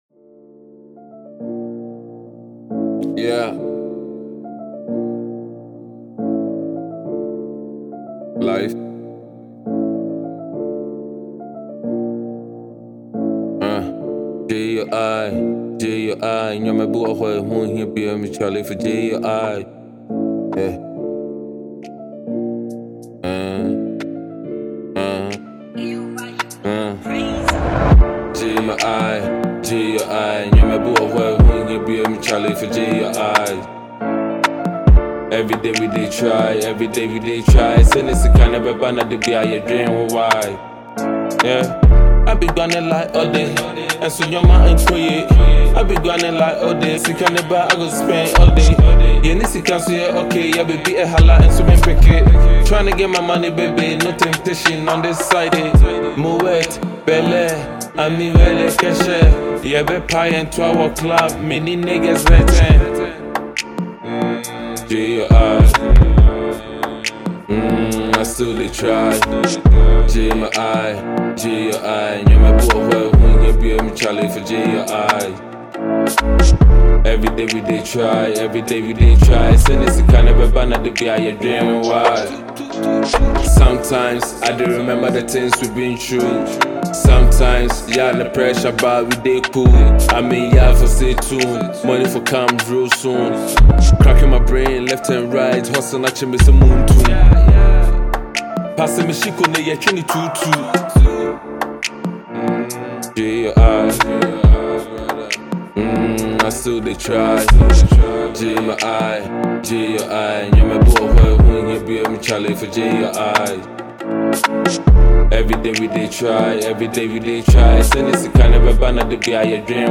Talented Ghanaian rapper